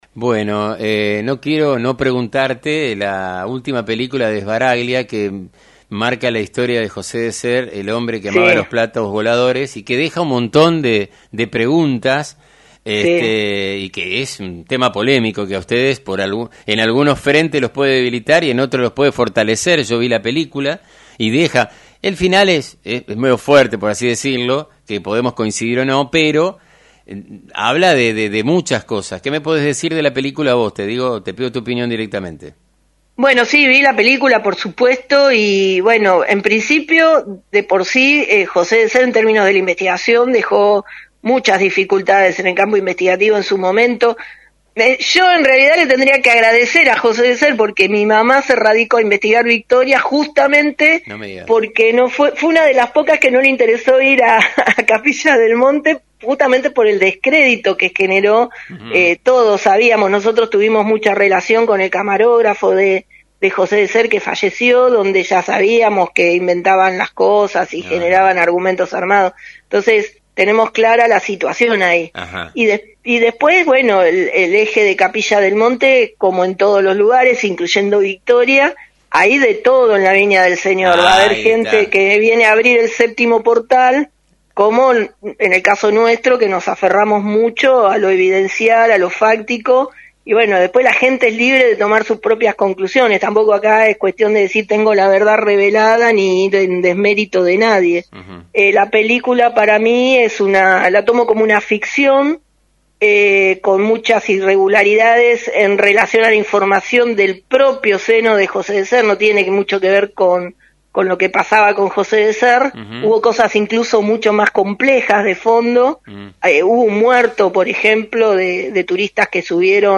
“A la película la tomo como una ficción con muchas irregularidades en relación a la información del propio seno del periodista.